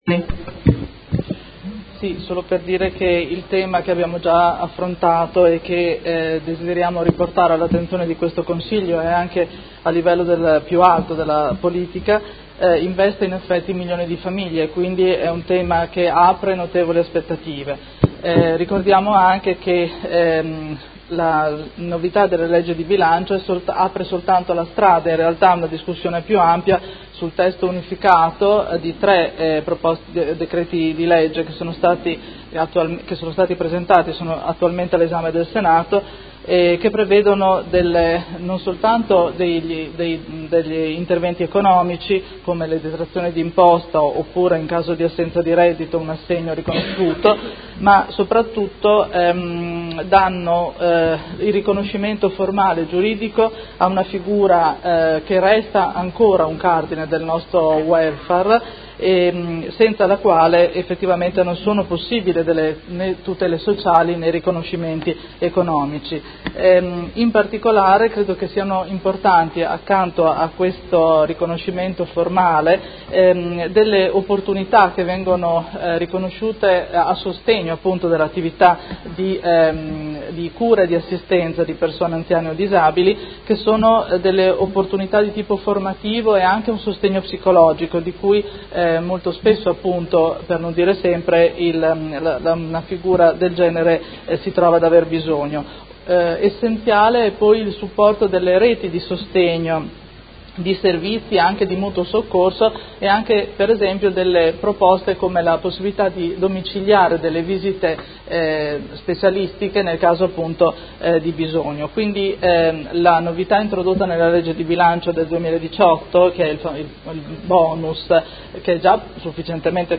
Chiara Pacchioni — Sito Audio Consiglio Comunale